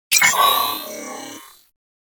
WeaponWarning.wav